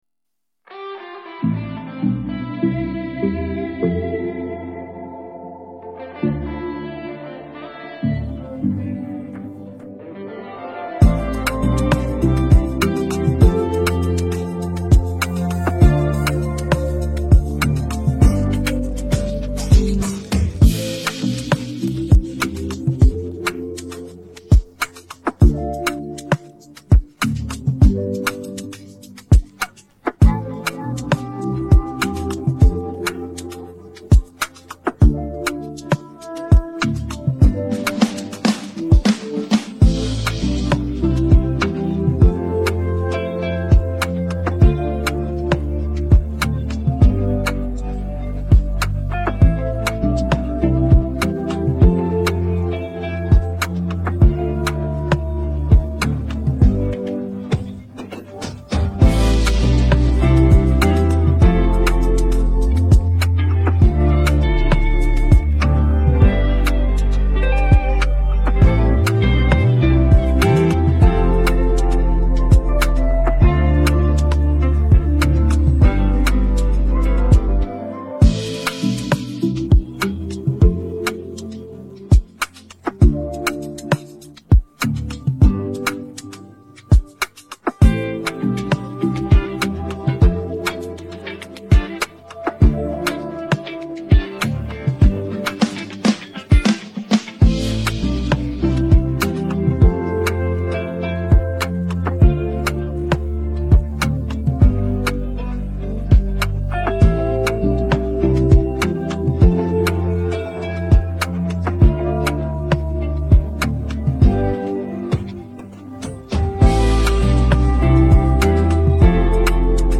• High-quality Afrobeat instrumental